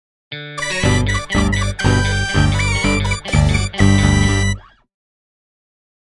Cartoon Sound
Anime Cartoon Sound